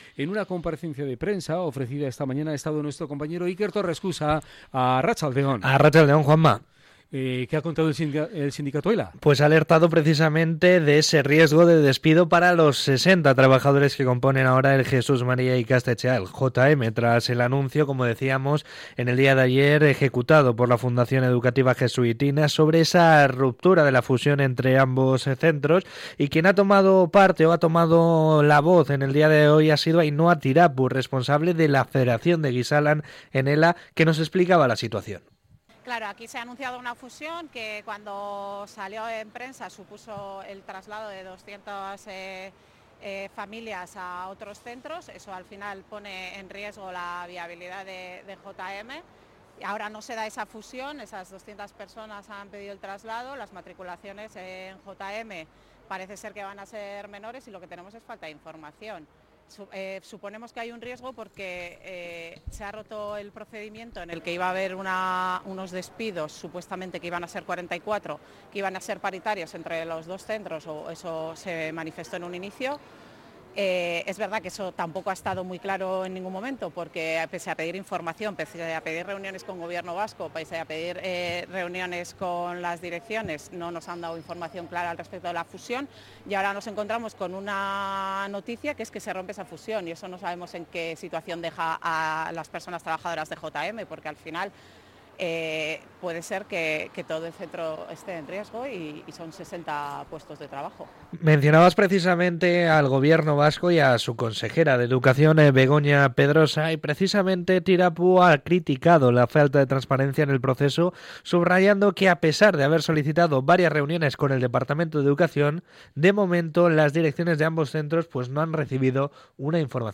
CRONICA-JM-JESUITINAS.mp3